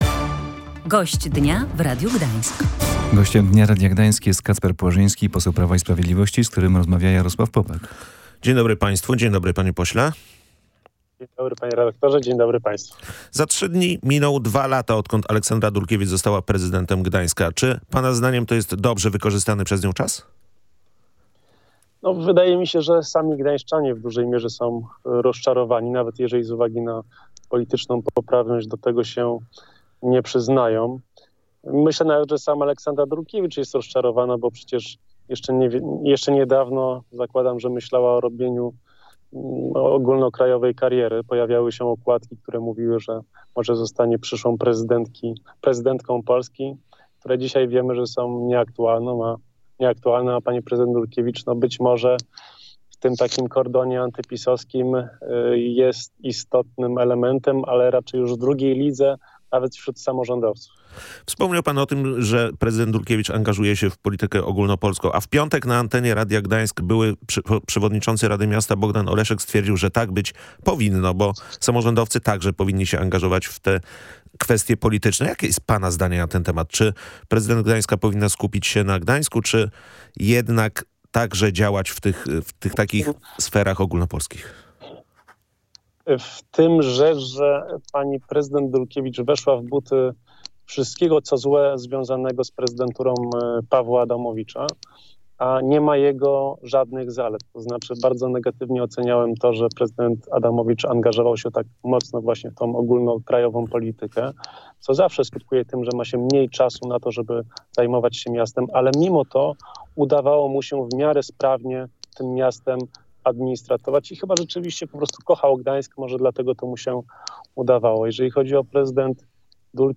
Zbyt duże zainteresowanie ogólnopolską polityką oraz małe zaangażowanie w sprawy miasta – tak dwa lata rządów Aleksandry Dulkiewicz ocenia poseł Prawa i Sprawiedliwości Kacper Płażyński.